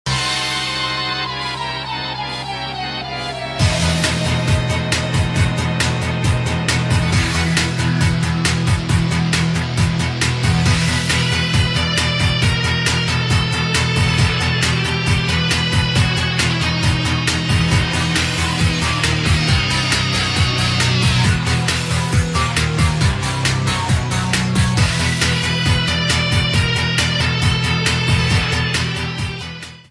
Background music
ripped from files (no background noise)